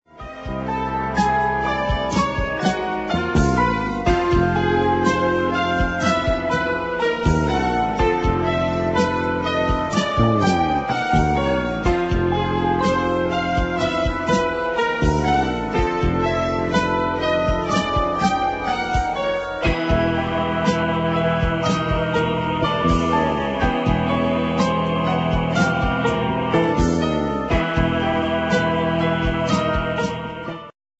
thrilling slow instr.